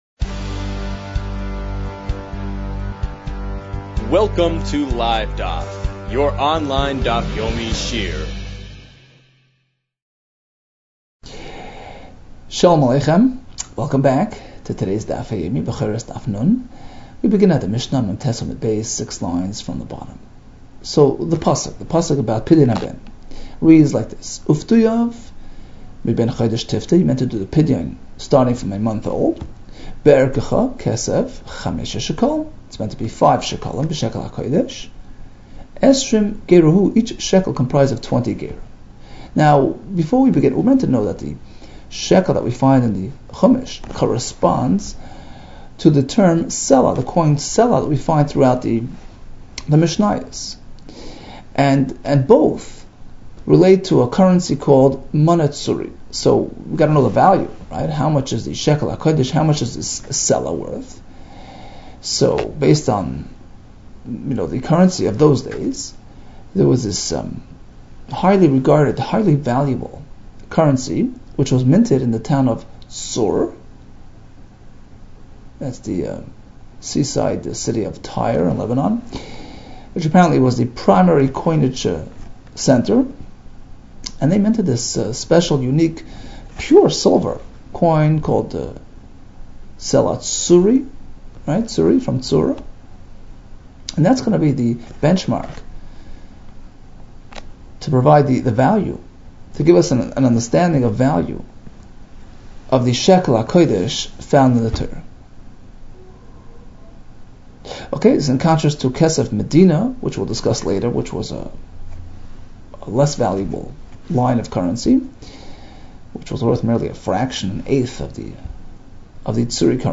Bechoros 50 - בכורות נ | Daf Yomi Online Shiur | Livedaf